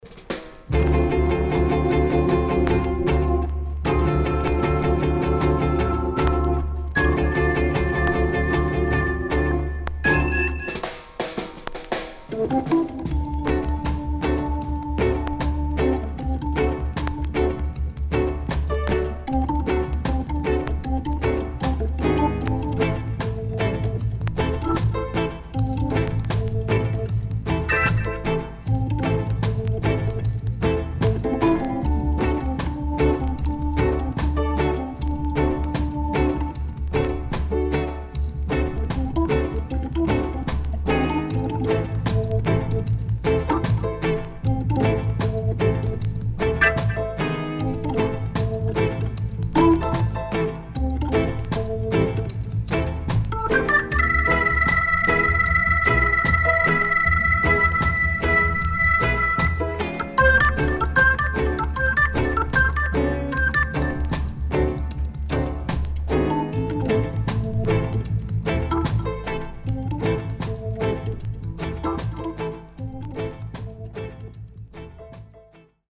SIDE B:少しチリノイズ、プチノイズ入ります。